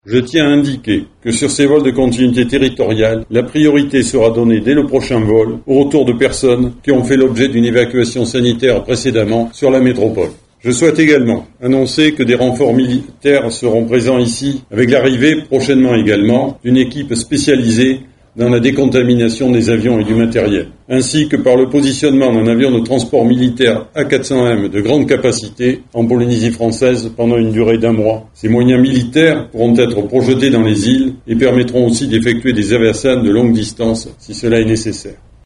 ALLOCUTION-3-militaires.mp3